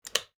Handbag Lock Sound Effect 3 A single metallic click sound Keywords: snap, click, metal
handbag-lock-3.wav